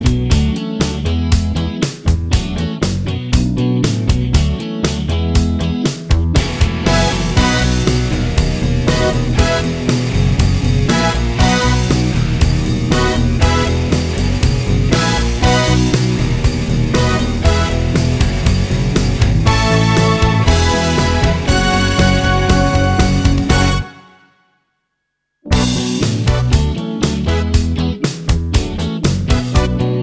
No Main Guitar Pop (1980s) 3:54 Buy £1.50